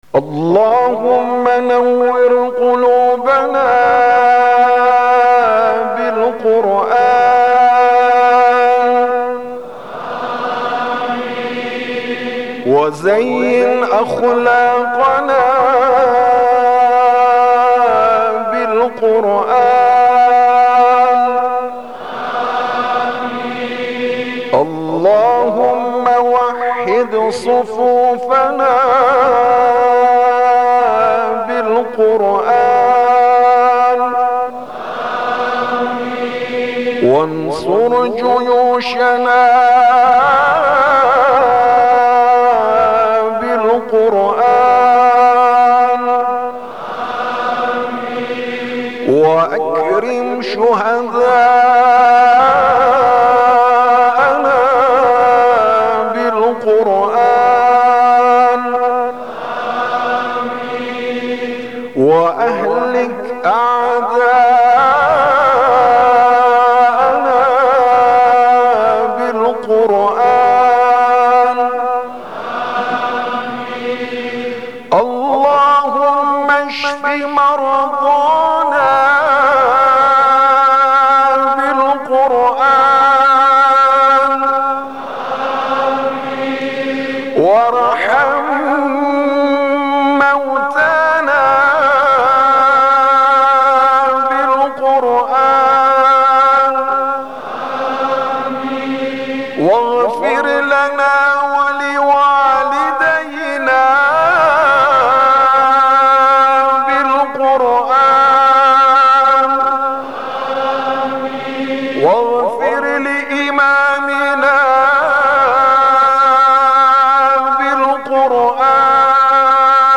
ابتهال